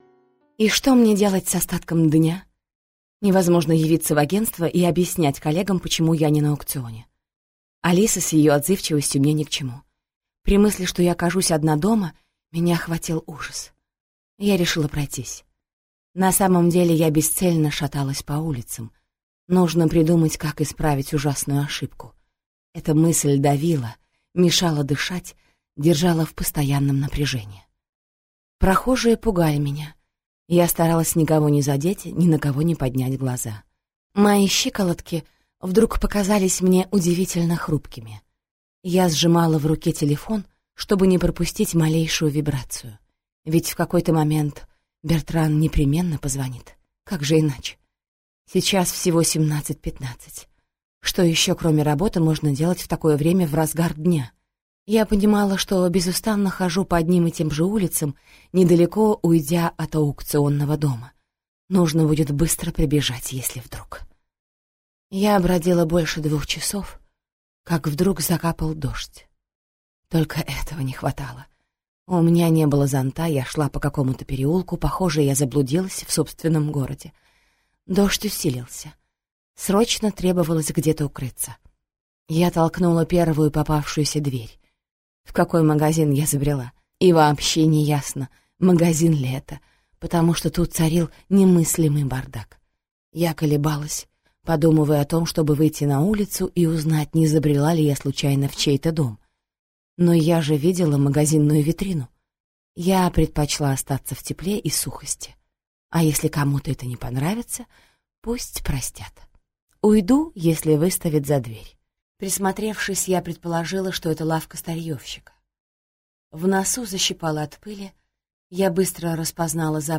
Аудиокнига Извини, меня ждут…